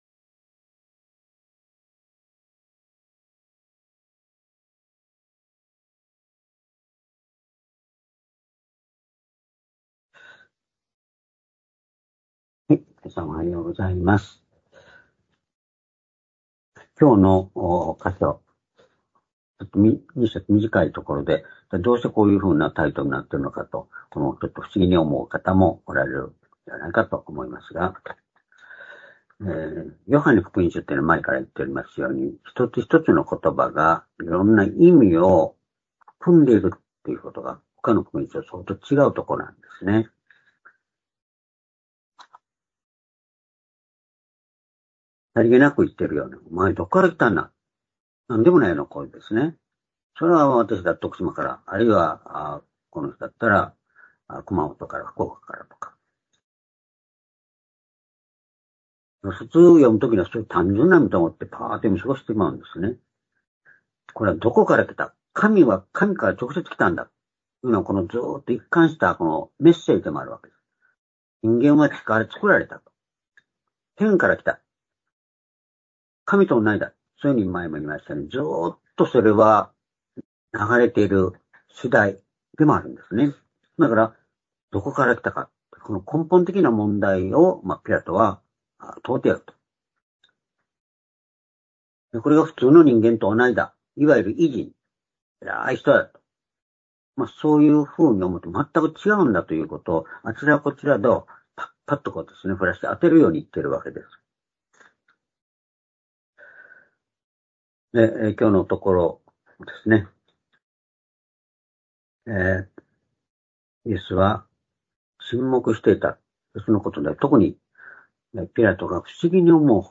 「神から与えられる権威（力）」-ヨハネ19章10節～11節―２０２４年12月1日（主日礼拝）
主日礼拝日時 ２０２４年12月1日（主日礼拝） 聖書講話箇所 「神から与えられる権威（力）」 ヨハネ19章10節～11節 ※視聴できない場合は をクリックしてください。